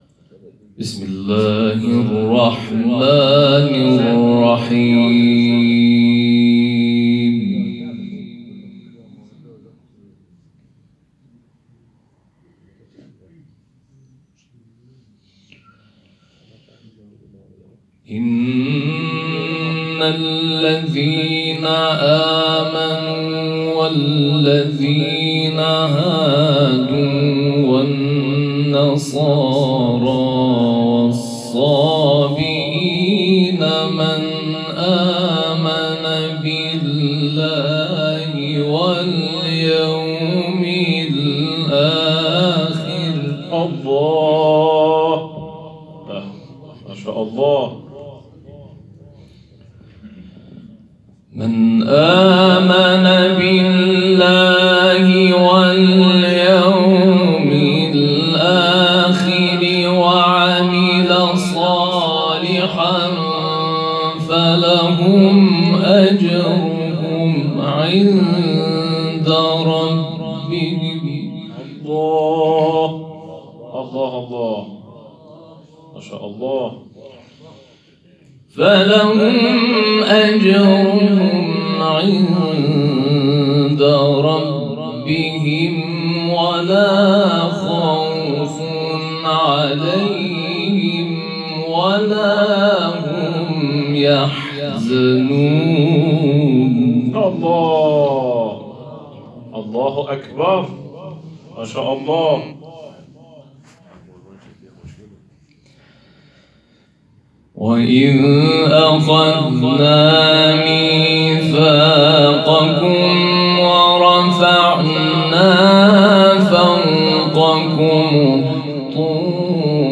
صوت تلاوت